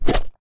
mob / slimeattack2